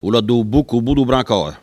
Elle provient de Saint-Jean-de-Monts.
Locution ( parler, expression, langue,... )